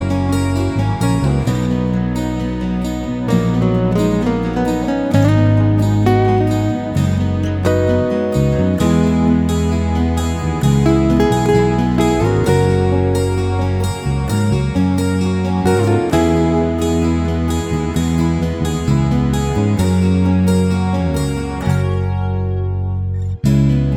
For Solo Female Pop